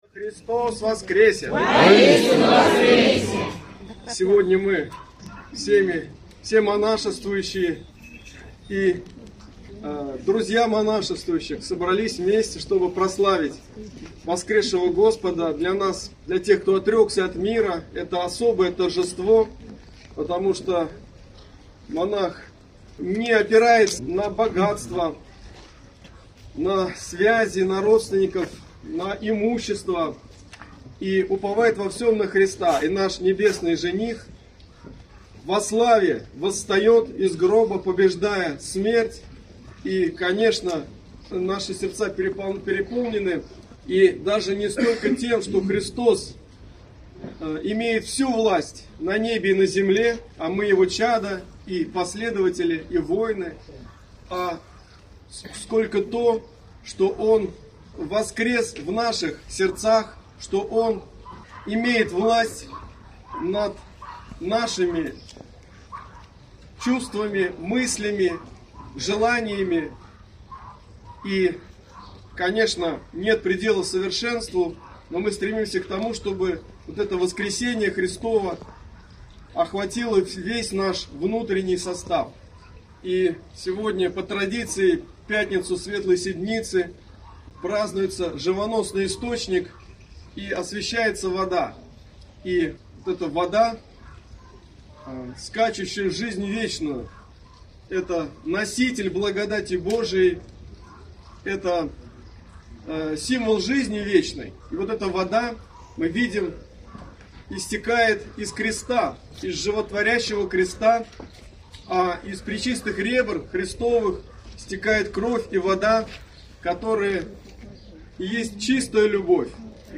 После отпуста богослужения Высокопреосвященнейший Владыка обратился ко всем присутствовавшим с проповедью.